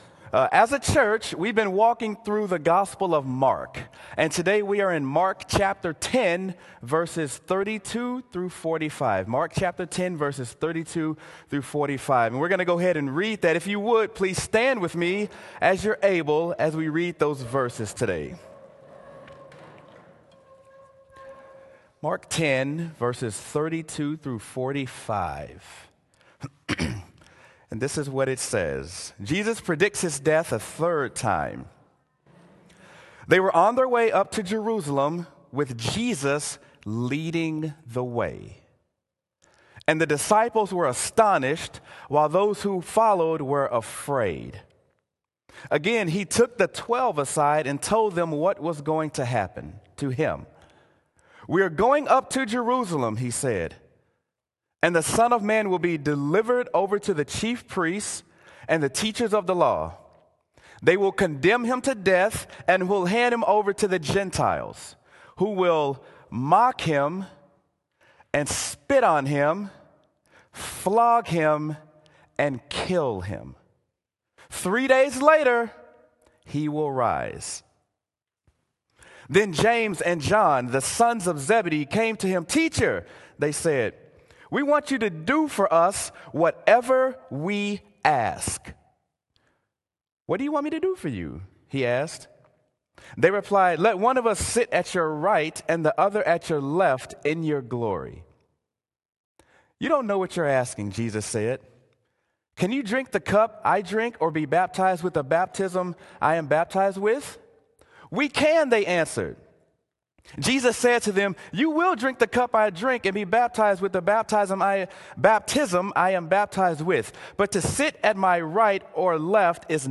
Sermon: Mark: Focused on Jerusalem
sermon-mark-focused-on-jerusalem.m4a